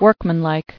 [work·man·like]